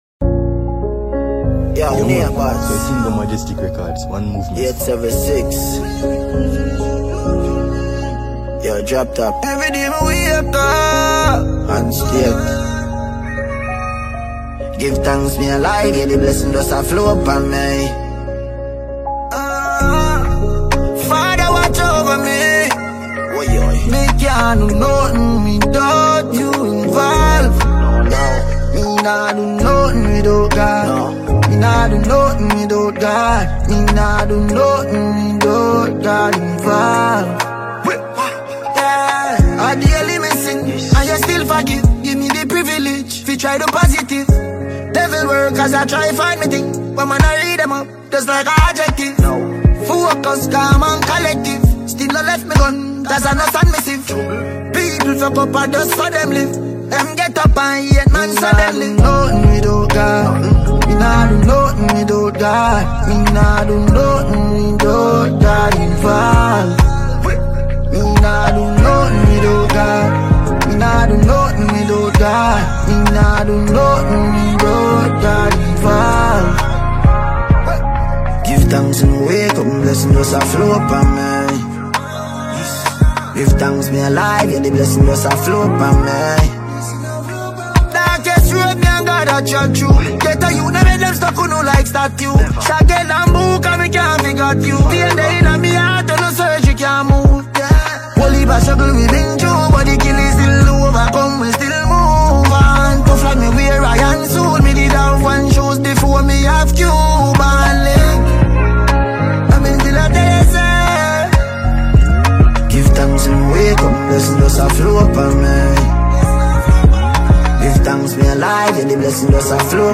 Multiple award-winning Jamaican dancehall act